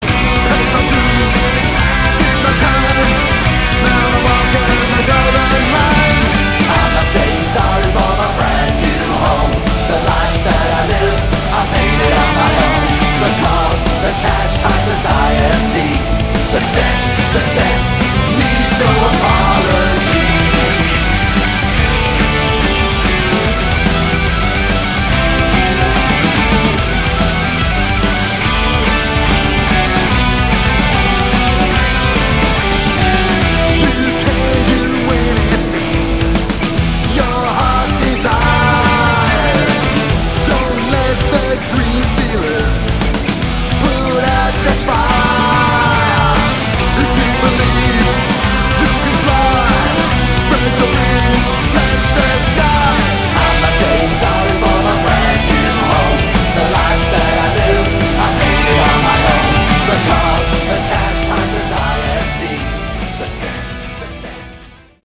Category: Hard Rock
lead vocals, guitars, keys
lead and rhythm guitars
drums
bass